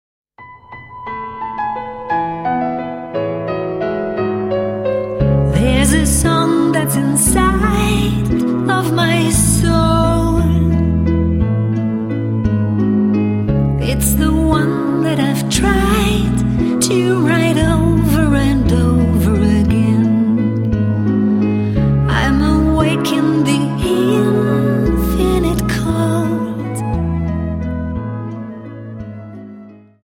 Viennese Waltz Song